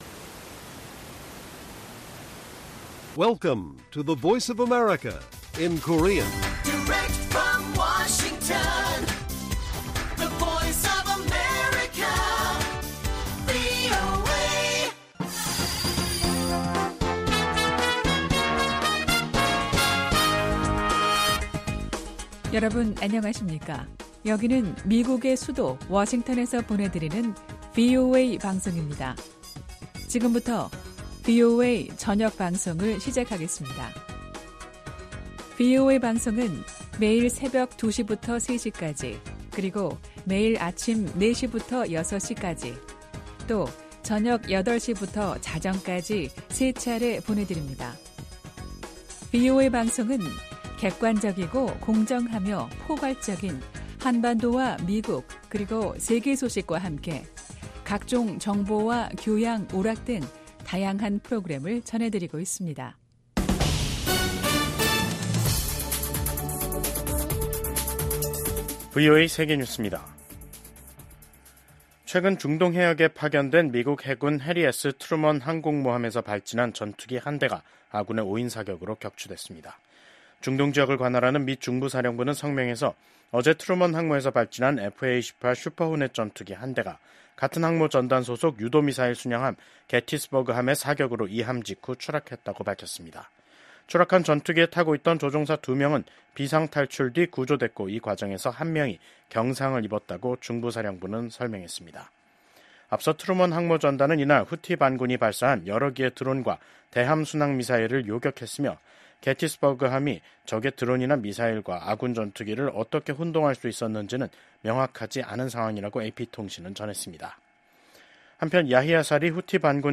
VOA 한국어 간판 뉴스 프로그램 '뉴스 투데이', 2024년 12월 23일 1부 방송입니다. 한국 군 당국은 북한이 러시아에 추가 파병을 하고 자폭형 무인기 등을 지원하려는 동향을 포착했다고 밝혔습니다. 대북송금 사건 공모 혐의로 최근 한국 정계 인사가 항소심에서 실형을 선고받은 가운데 미국 국무부는 각국의 대북제재 이행의 중요성을 강조했습니다. 중국을 견제하기 위한 조선업 강화 법안이 미국 의회에 초당적으로 발의됐습니다.